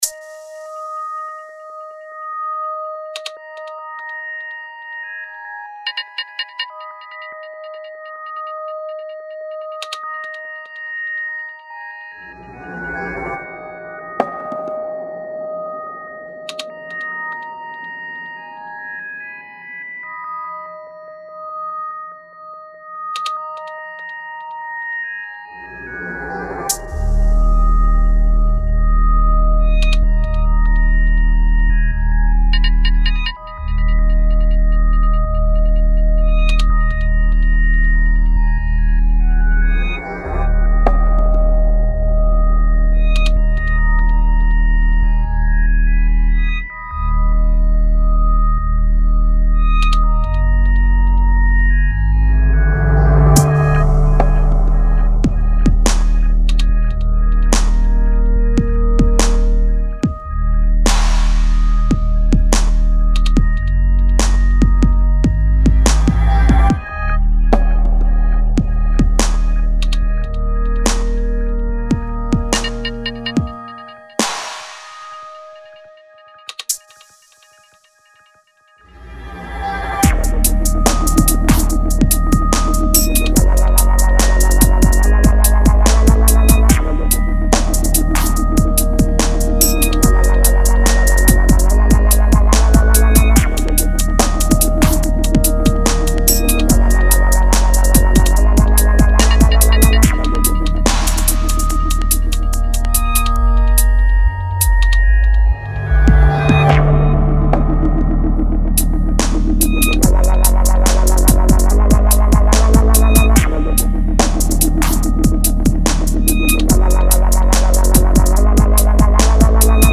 nice and big!
made on a 20euros headphone so don't be to hard on the mixdown ;)
out of body dub
maybe boost the sub a touch?
Nice wobbler!!!!